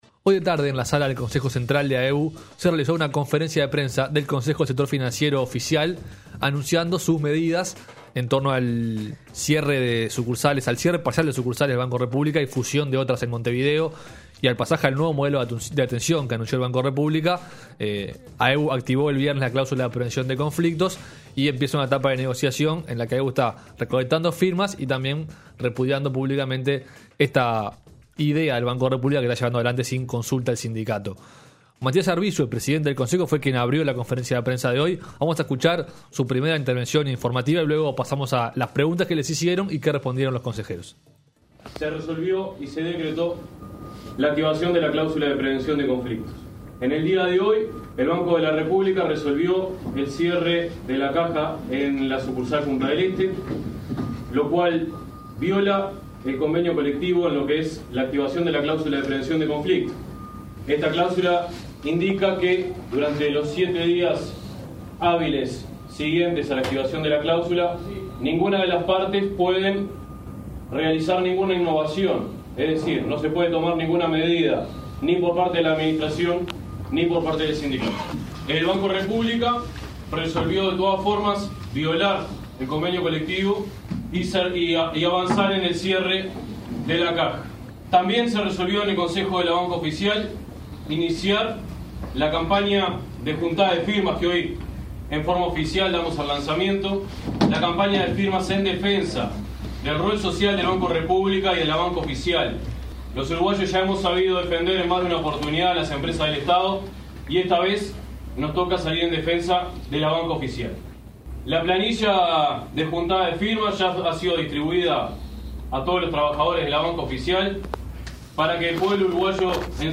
Este lunes el Consejo de Banca Oficial de AEBU dio una conferencia de prensa. Escuchamos las intervenciones y respuestas de los dirigentes en Camacuá y Reconquista.